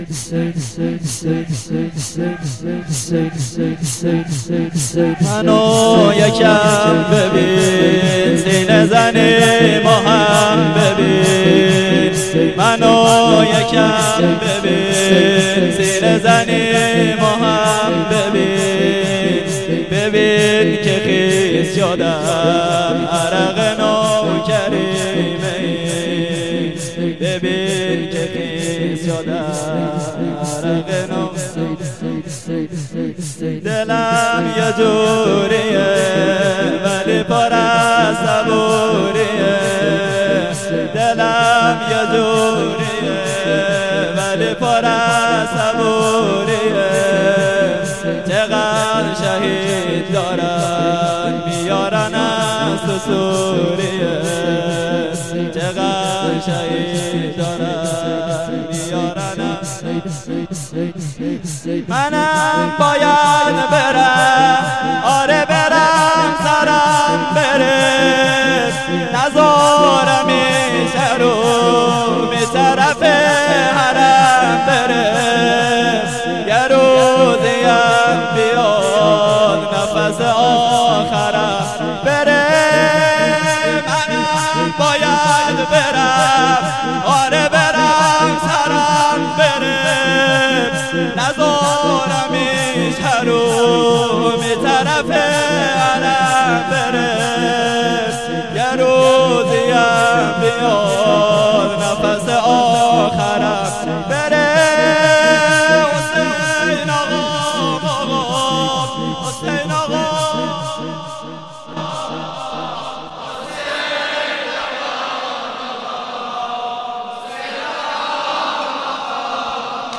خیمه گاه - هیئت قتیل العبرات - مداحی